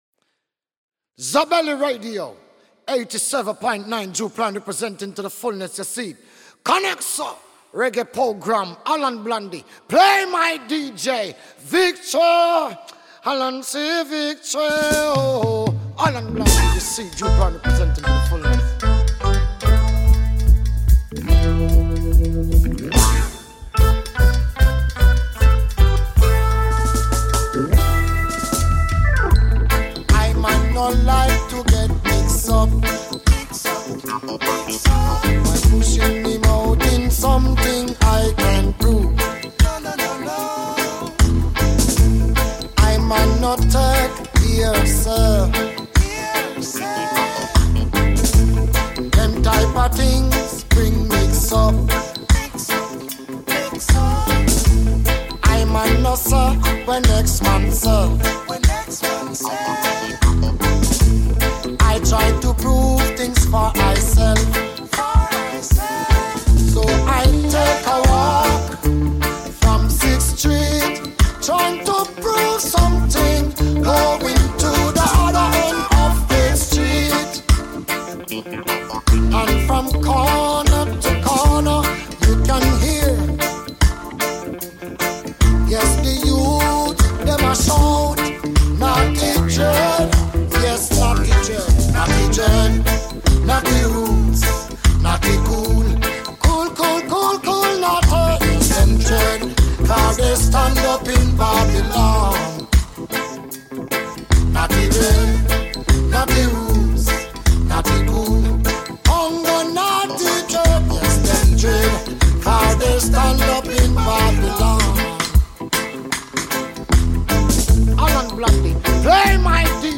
reggae Jamaicano
Sua voz poderosa e profunda